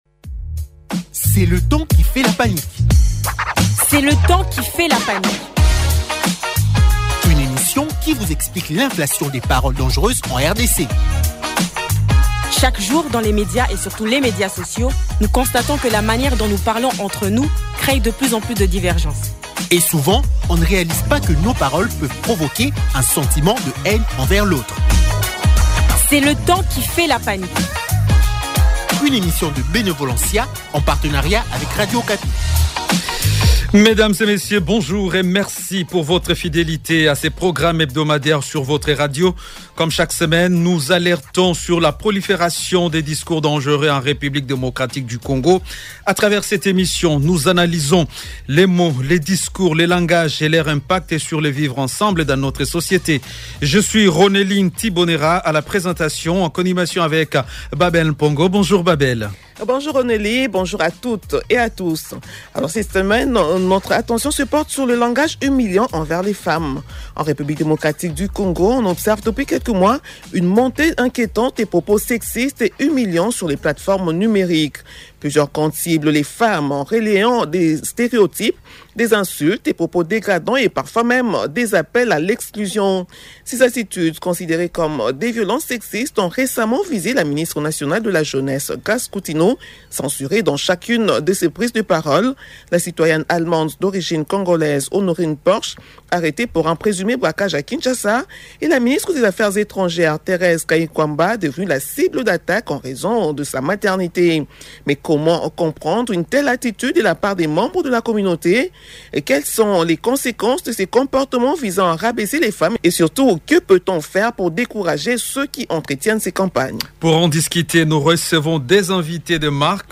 Pour en débattre, deux invités deux invités sont avec nous